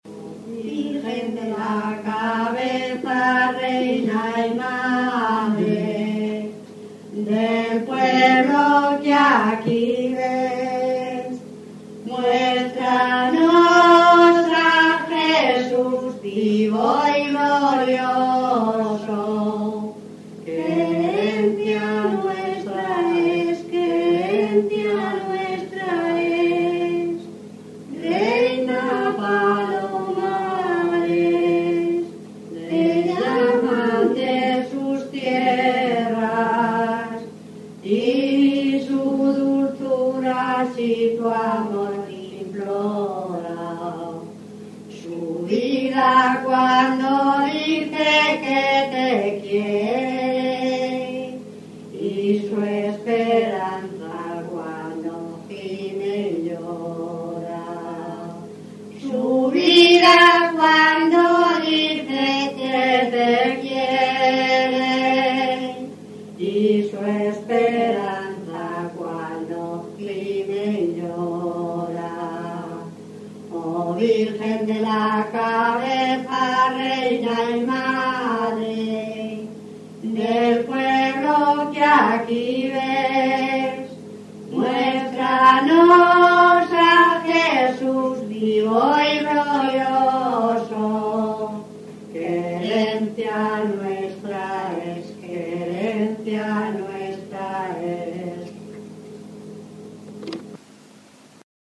himno-virgen-de-la-cabeza.mp3